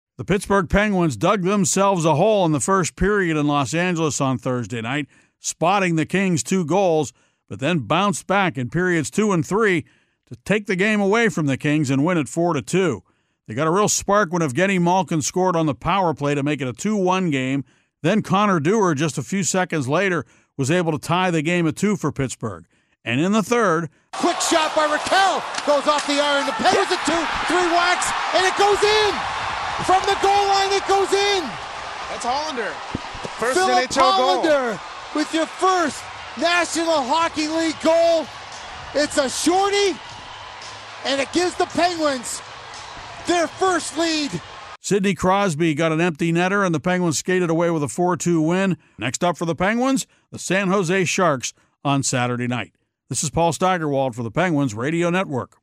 The Penguins got a surprise goal – short-handed and from a rookie who’d never scored in the NHL before – as they rallied from behind and beat the LA Kings last night.  Paul Steigerwald has the recap.